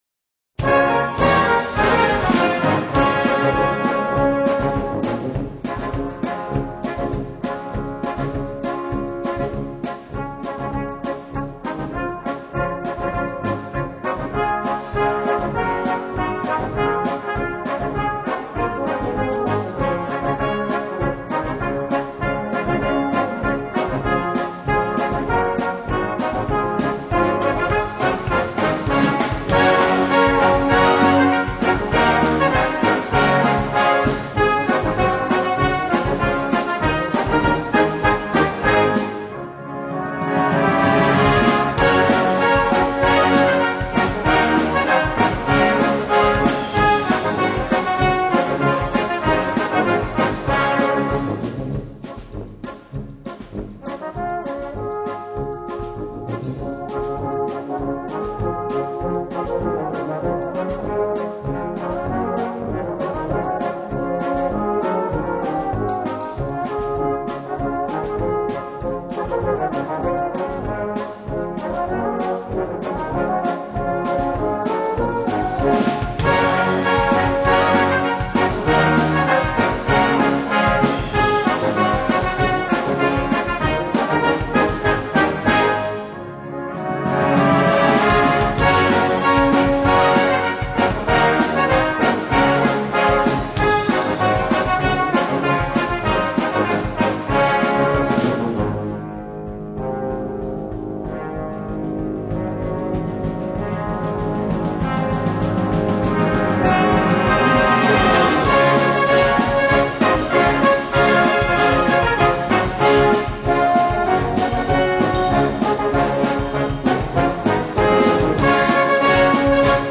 Gattung: Folk Hit
Besetzung: Blasorchester